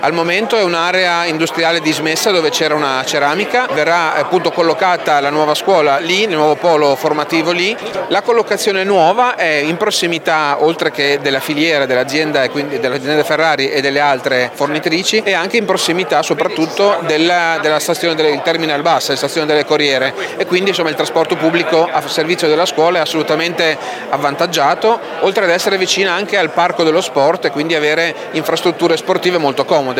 Dove sorgerà il nuovo e avveniristico complesso? Ce lo spiega il sindaco di Maranello Luigi Zironi: